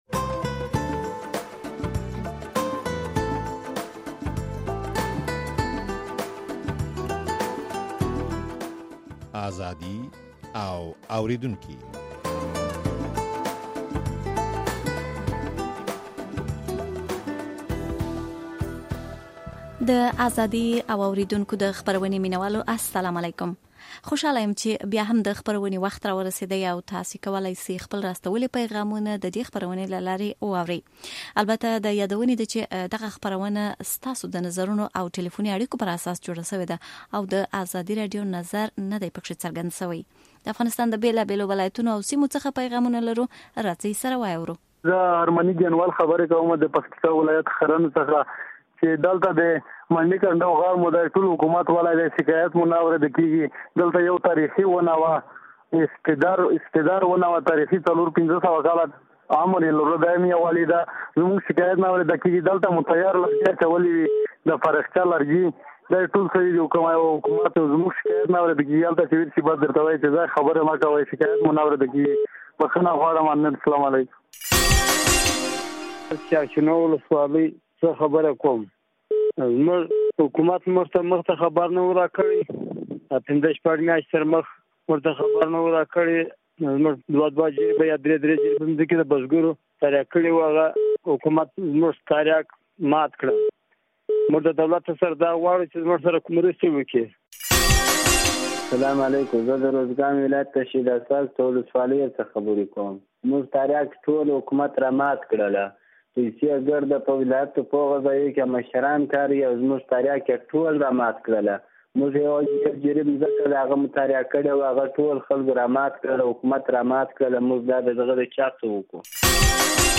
په دې پروګرام کې پر بېلابېلو ستاسو ټليفوني پيغامونه خپروو.